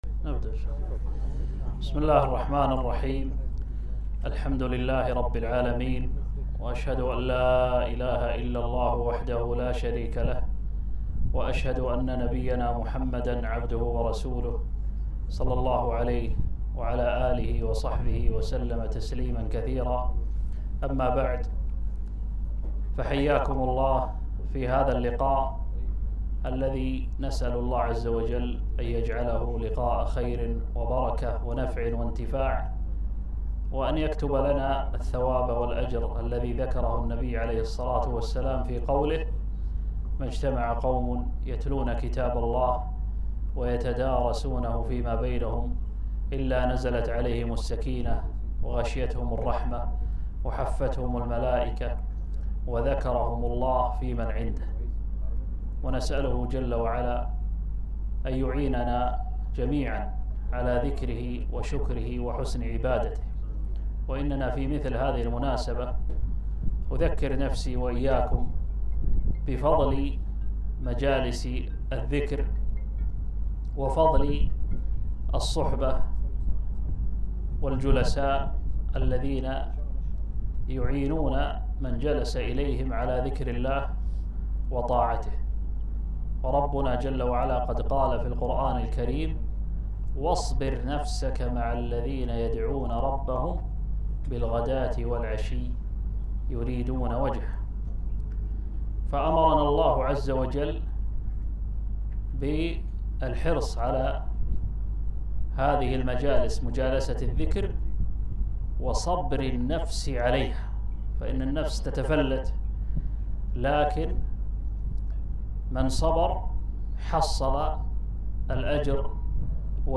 محاضرة - صلاح القلوب